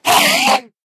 Minecraft Version Minecraft Version 1.21.5 Latest Release | Latest Snapshot 1.21.5 / assets / minecraft / sounds / mob / endermen / scream3.ogg Compare With Compare With Latest Release | Latest Snapshot
scream3.ogg